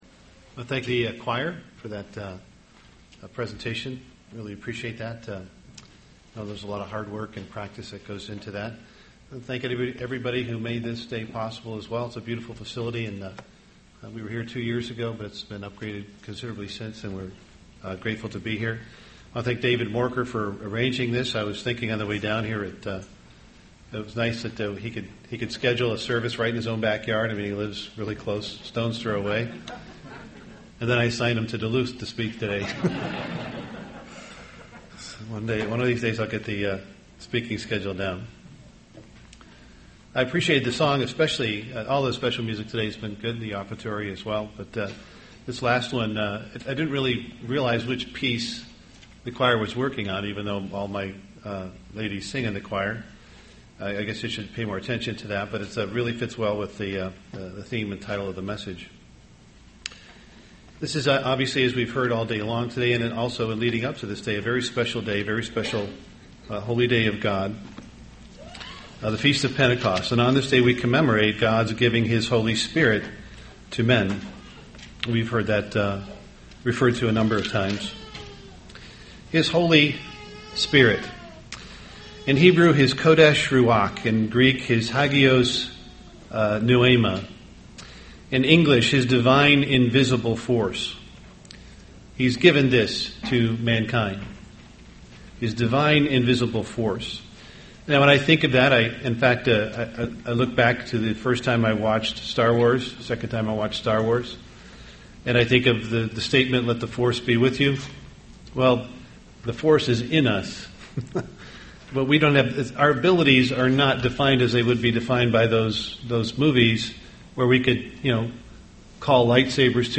Sermon
Given in Twin Cities, MN